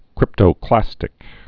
(krĭptō-klăstĭk)